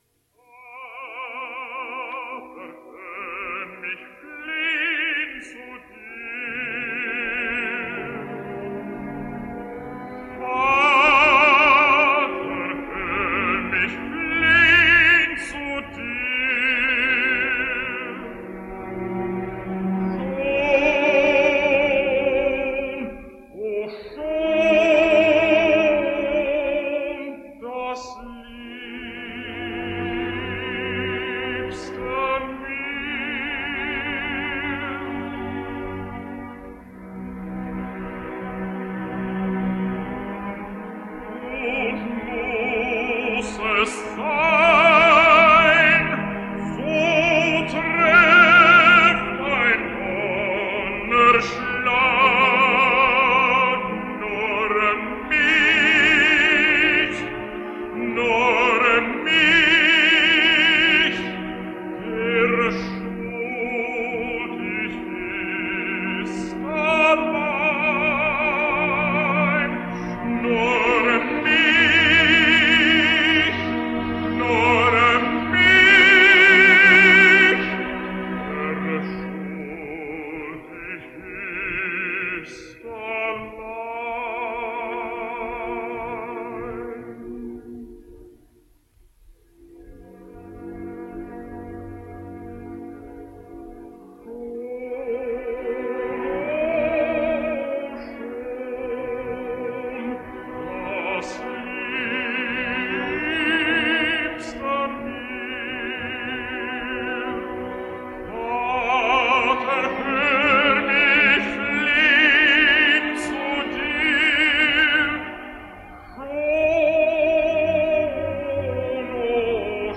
American Tenor